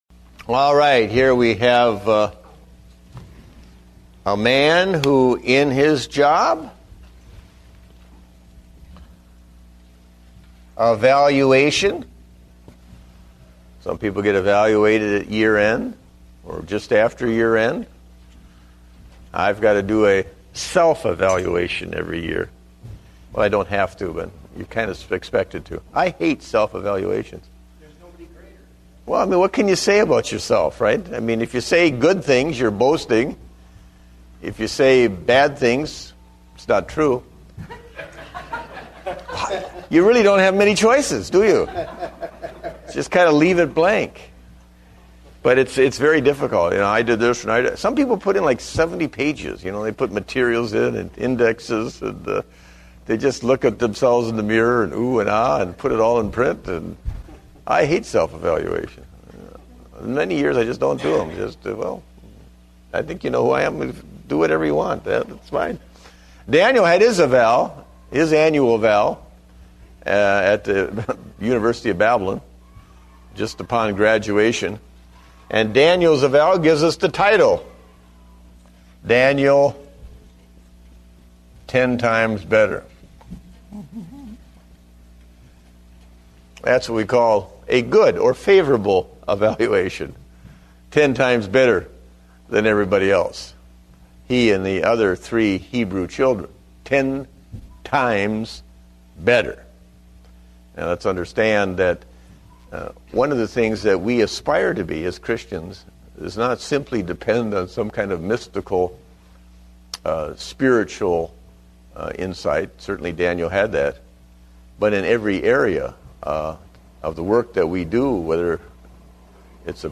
Date: December 13, 2009 (Adult Sunday School)